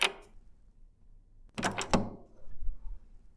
ホテル
ドア開けるカードキー
hotel_card_key.mp3